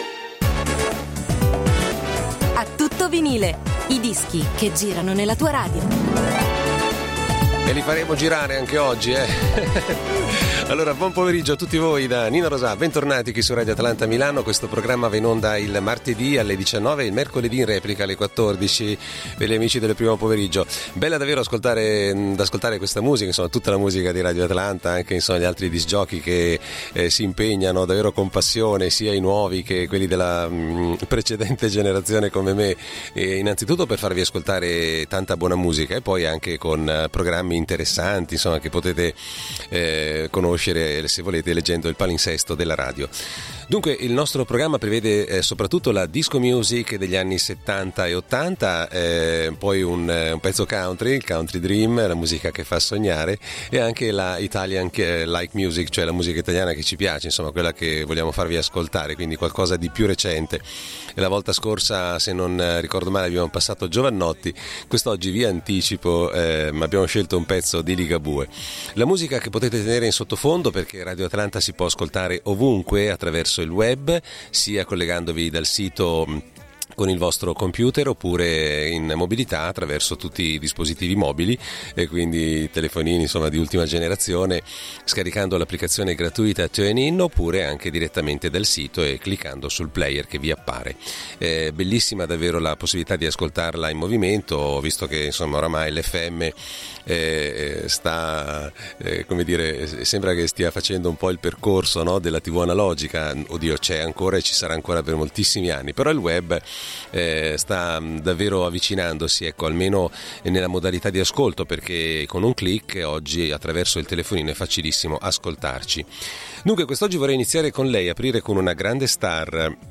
Musica “a tutto Vinile” ma anche notizie sugli artisti senza tempo della black dance, considerati i primi della classe.